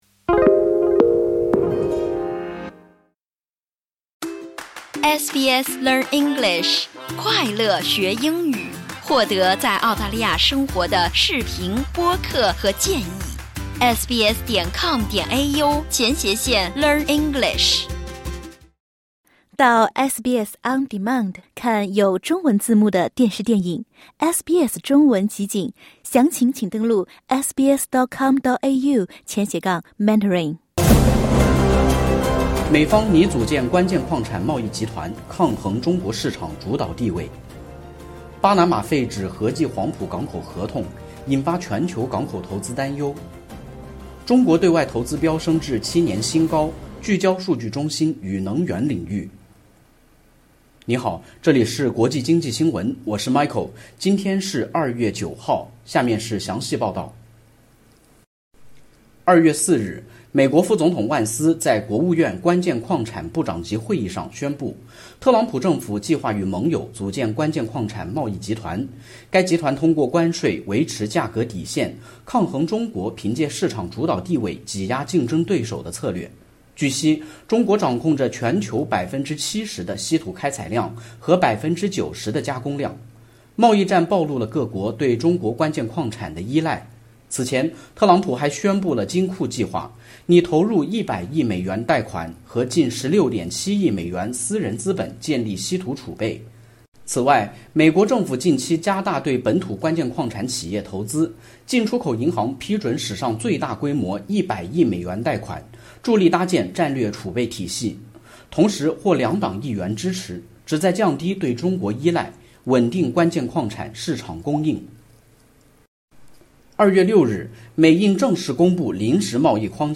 国际经济新闻（2月6日）： 分析师表示，特朗普关税政策、中东局势等加剧市场不安，贵金属成避险首选，叠加中印等国基础设施需求拉动铜价创新高，共同推高了奖牌成本。